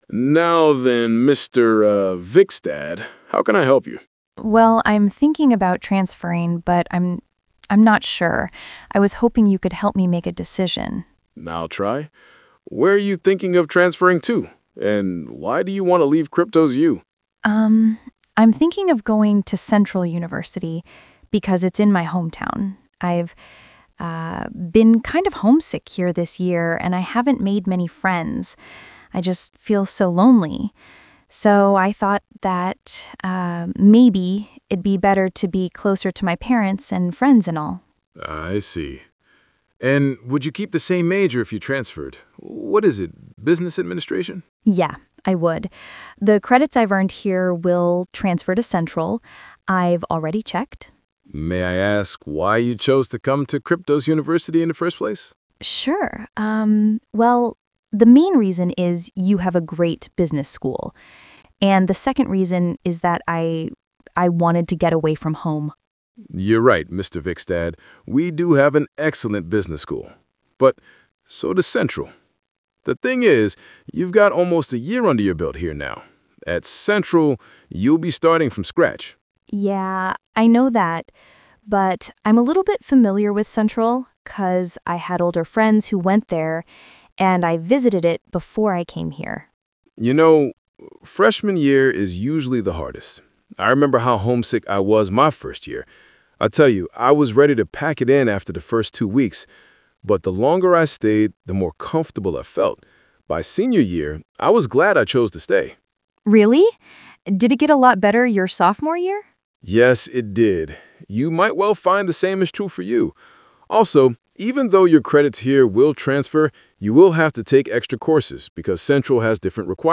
Conversation 3: Listen to a conversation between a student and a university advisor.